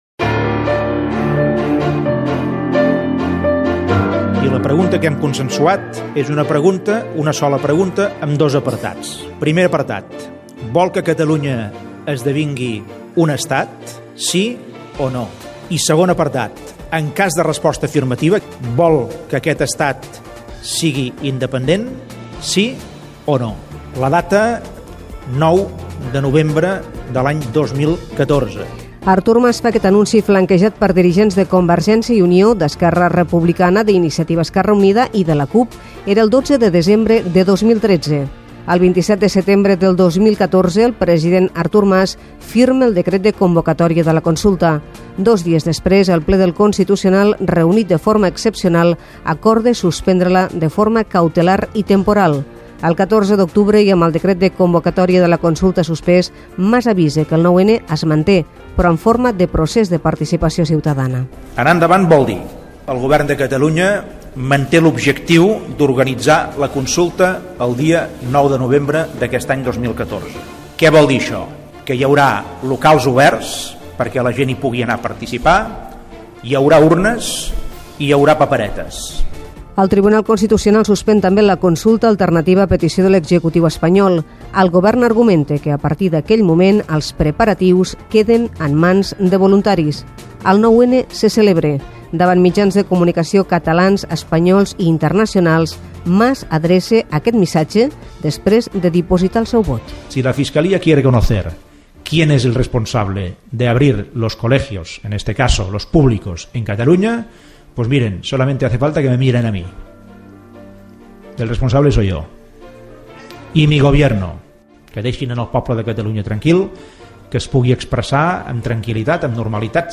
Reportatge: El judici del 9-N - Catalunya Ràdio, 2017